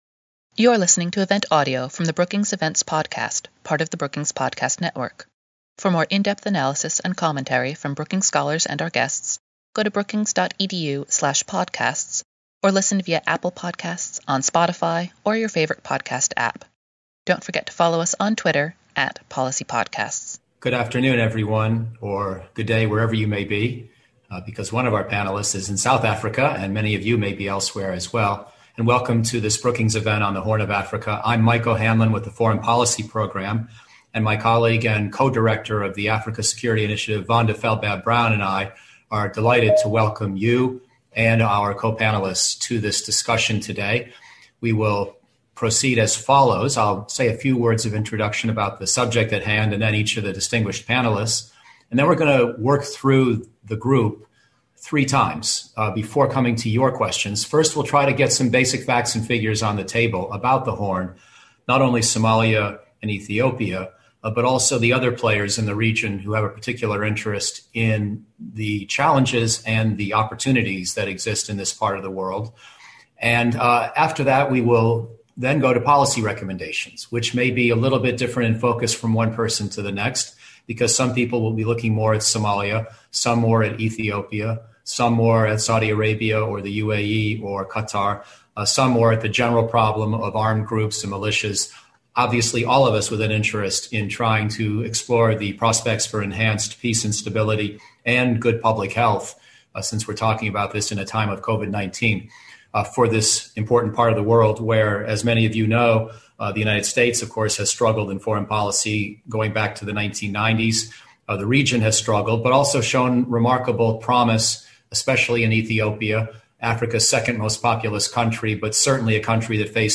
On September 28, the Africa Security Initiative at Brookings will hold a panel discussion to explore the political, security, and public health dimensions in the Horn of Africa.
Upon the conclusion of their remarks, panelists took questions from the audience.